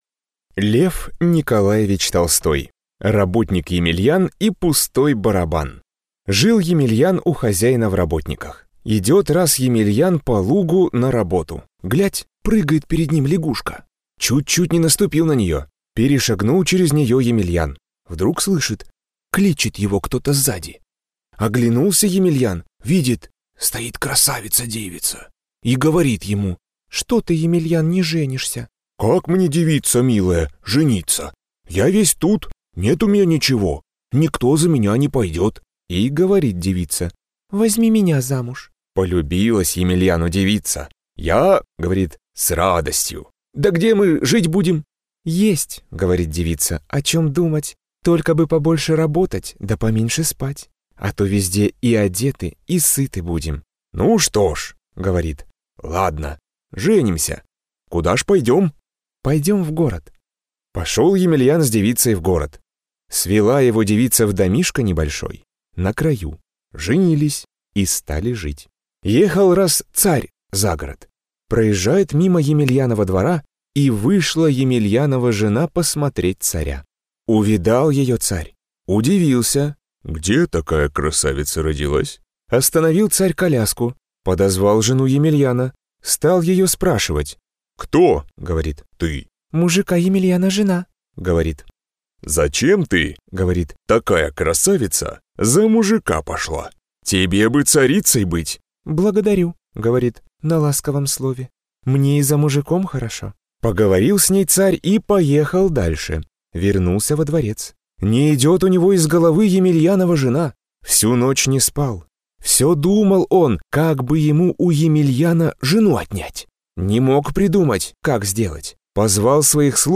Аудиокнига Работник Емельян и пустой барабан | Библиотека аудиокниг